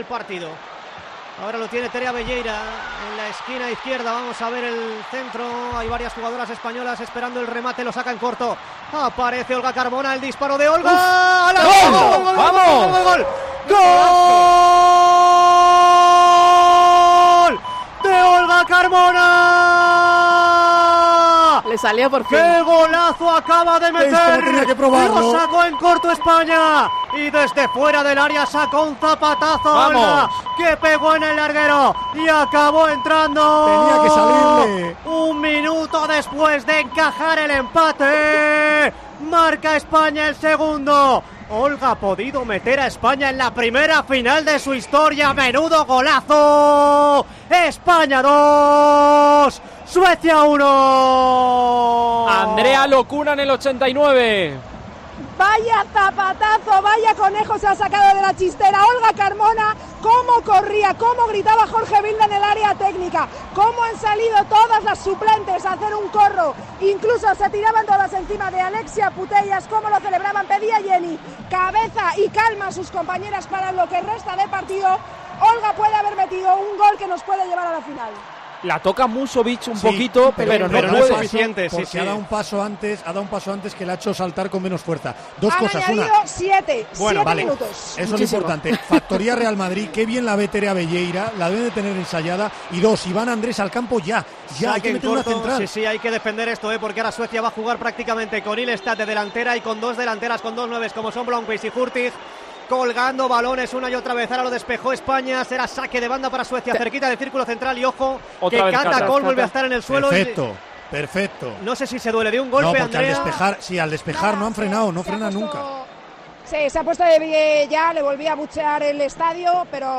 Revive la retransmisión del España-Suecia en Tiempo de Juego